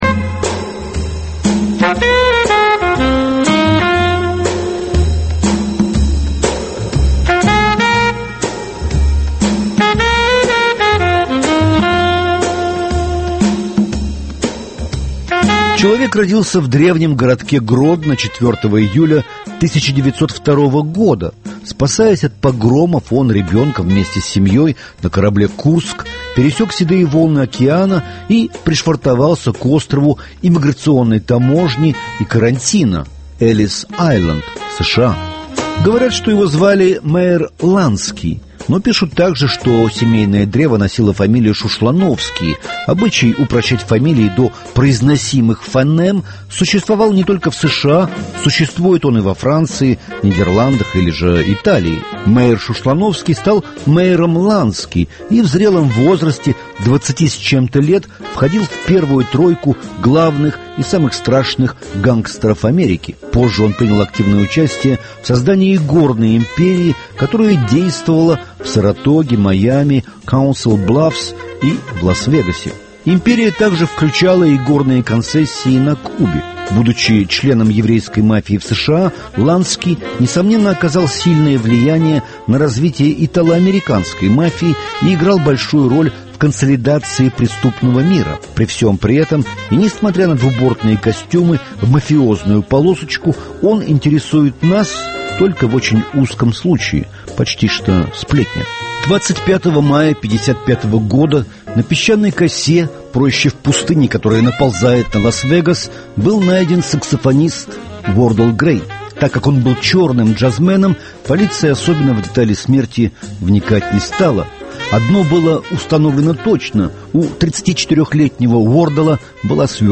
Саксофон Грея открывает еженедельное шоу «Времени Джаза».
Во «Времени Джаза» впервые использованы оцифрованные виниловые диски.